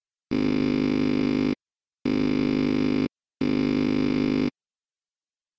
For now we will set the envelope to zero attack, zero decay and full sustain, to give a simple on/off effect.